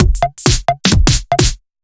OTG_Kit 2_HeavySwing_130-D.wav